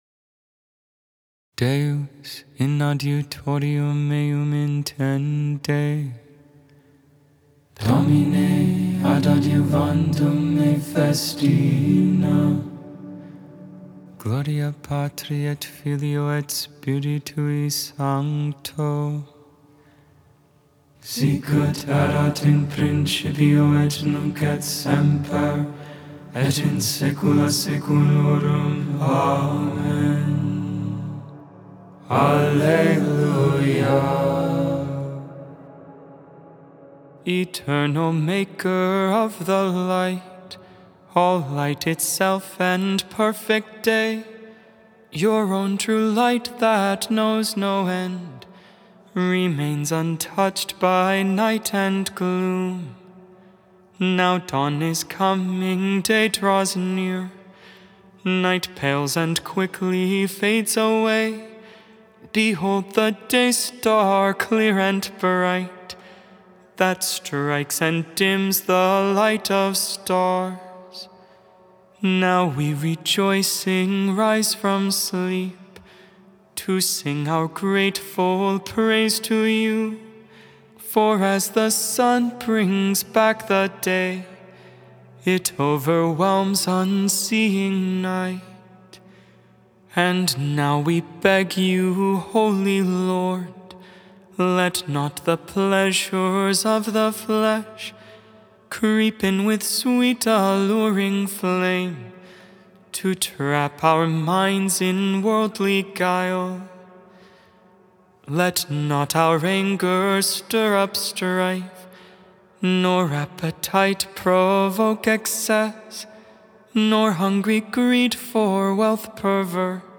Gregorian tone 8